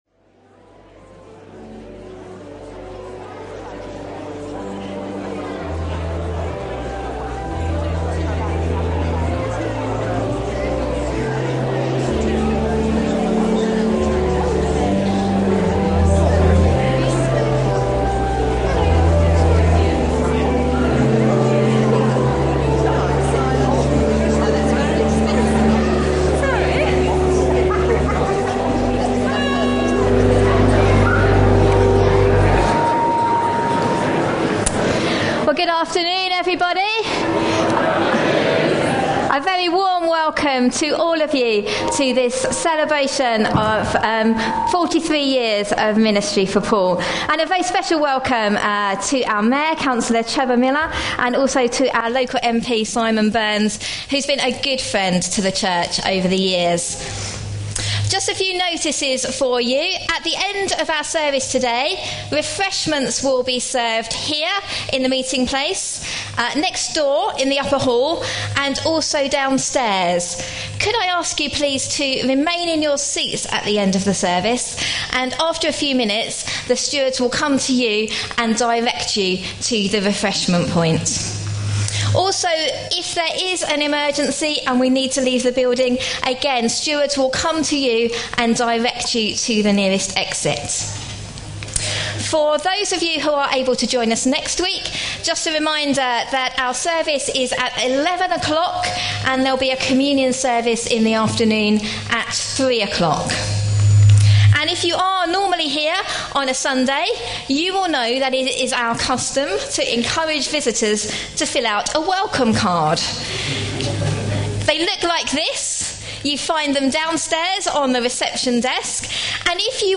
A sermon preached on 9th March, 2014.